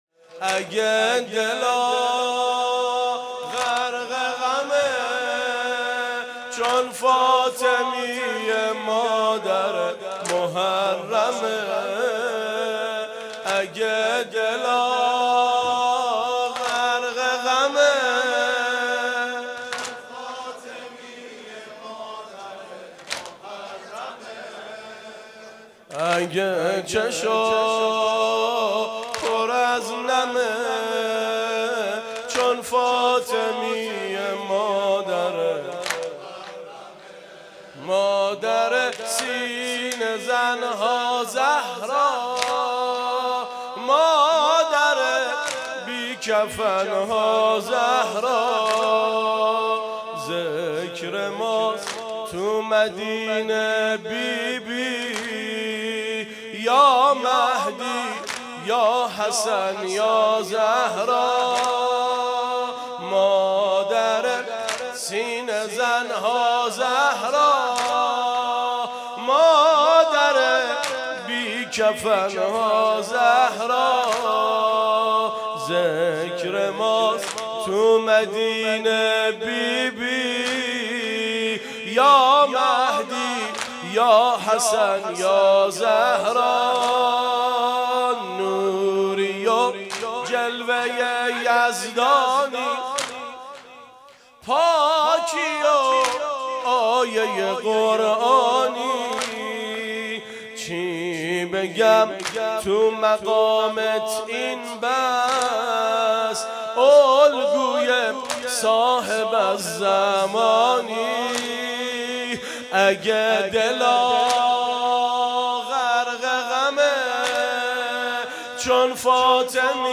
فاطمیه 1396